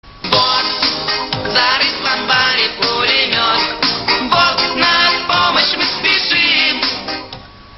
Песню слышали?